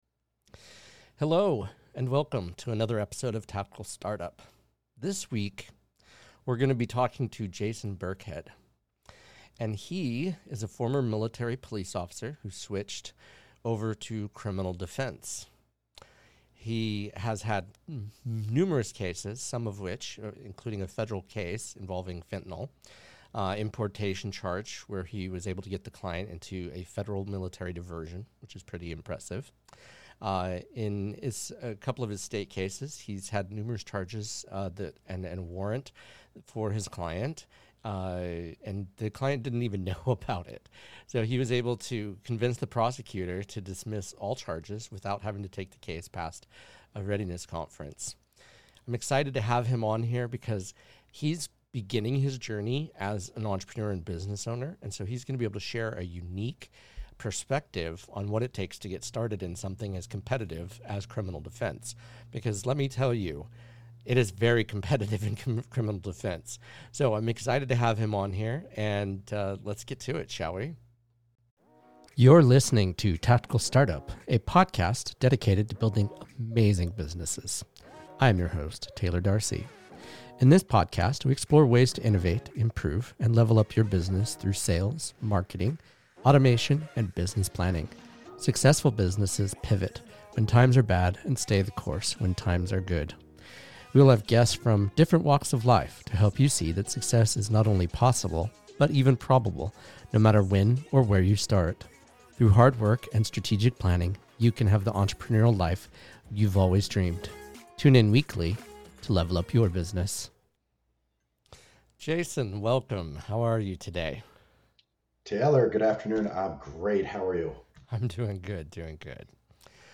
Shure SM7B Microphone
Rode Rodecaster Pro